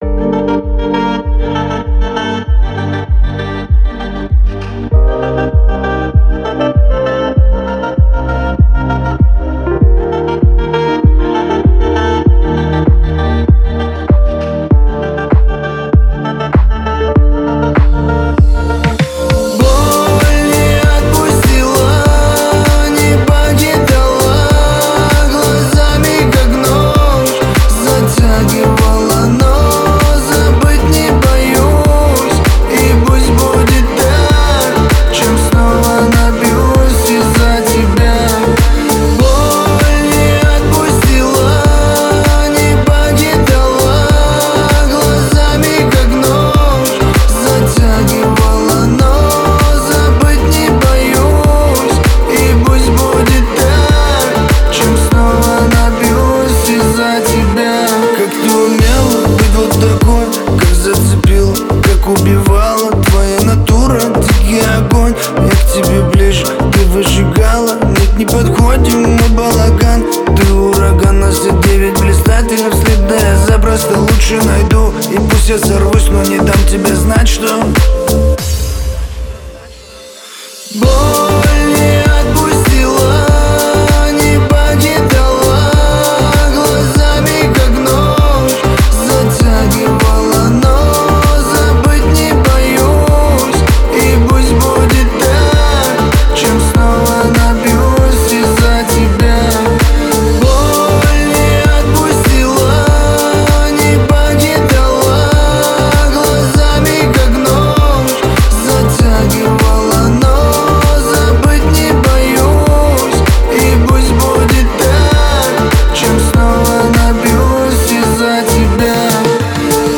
танцевальные песни